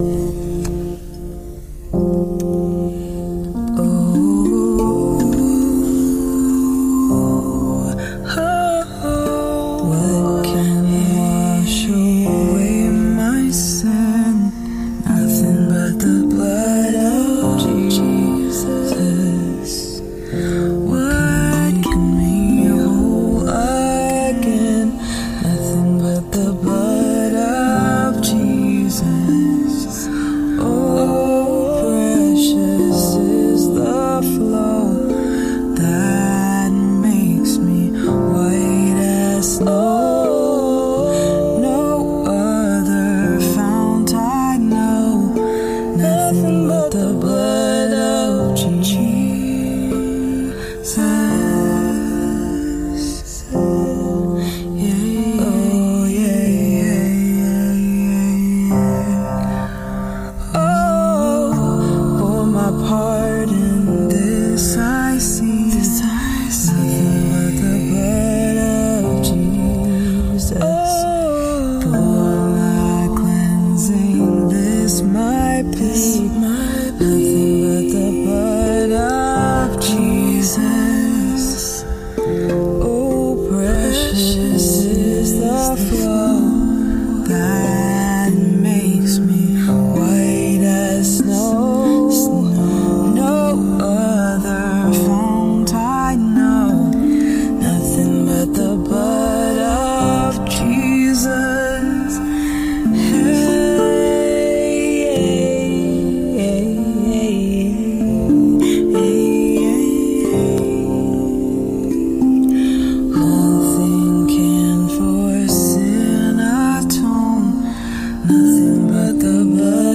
hymns
Rav vast drum
tongue drum